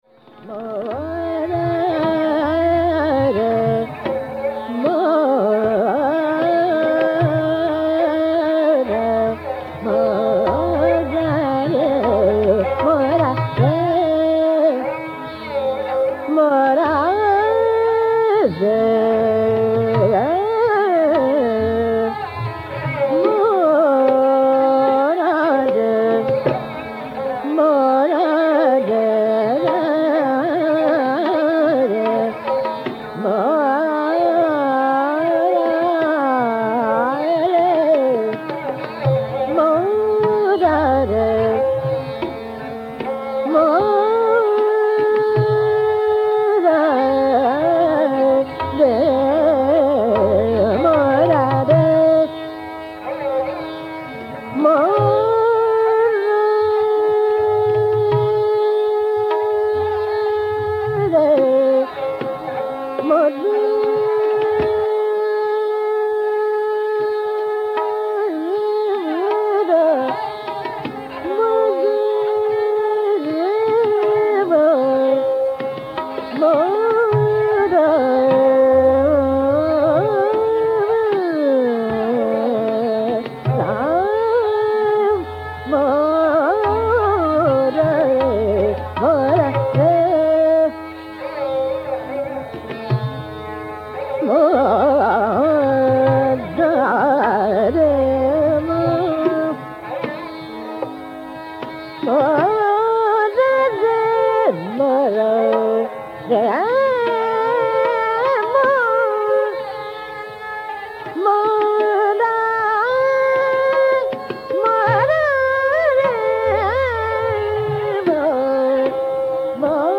In this Poorvi-that variant the accent is on komal dhaivat and rishab.  The affiliation to Poorvi is asserted through clusters such as P m d, P.